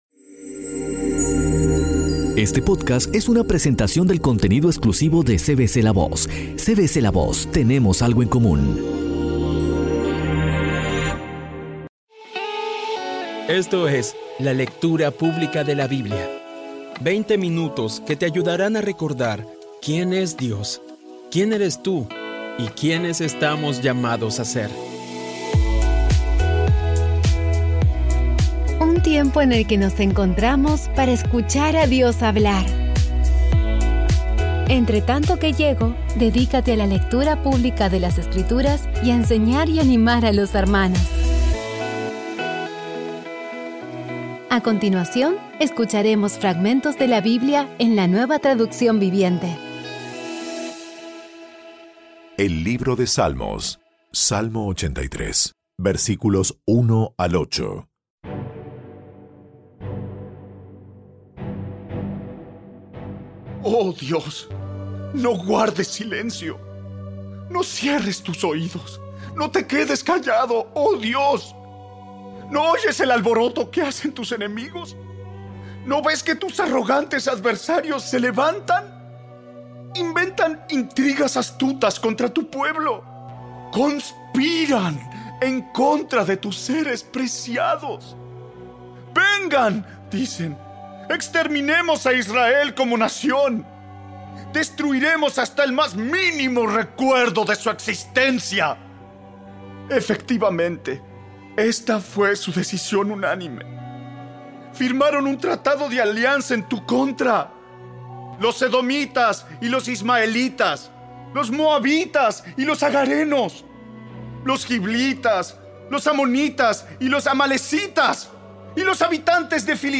Audio Biblia Dramatizada Episodio 200
Poco a poco y con las maravillosas voces actuadas de los protagonistas vas degustando las palabras de esa guía que Dios nos dio.